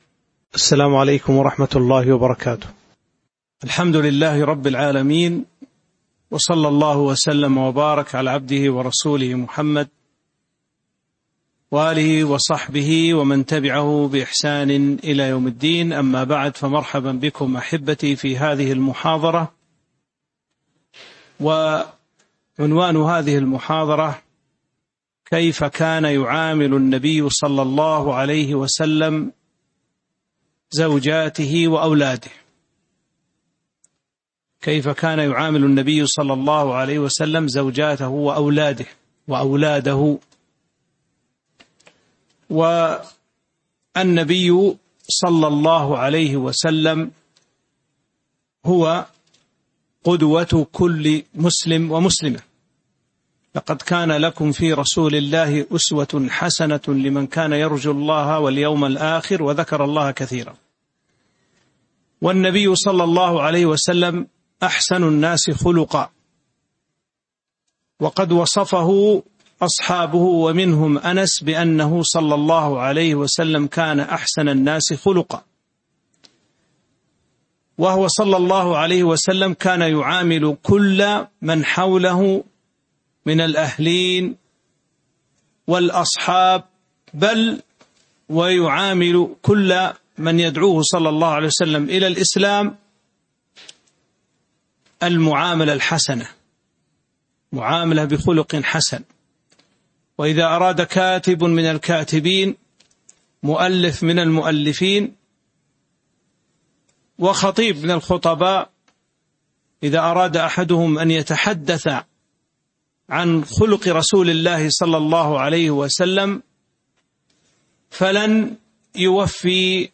تاريخ النشر ٦ جمادى الأولى ١٤٤٤ هـ المكان: المسجد النبوي الشيخ